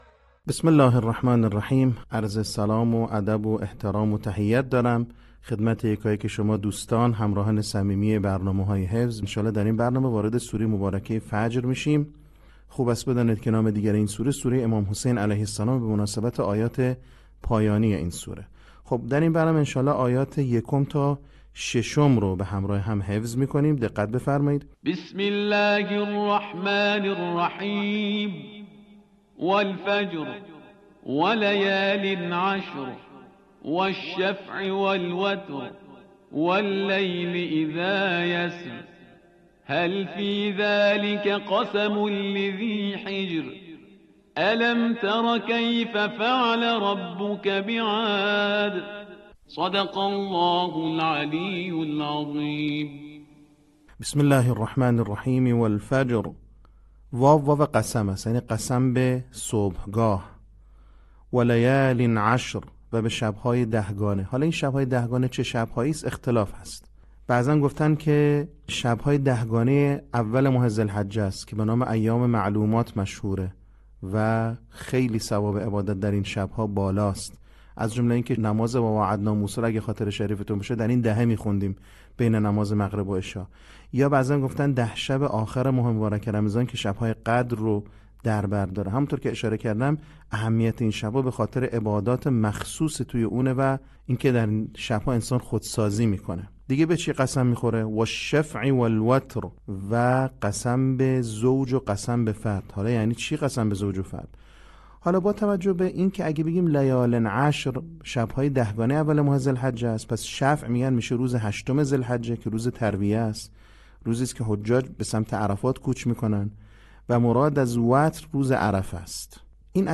صوت | آموزش حفظ سوره فجر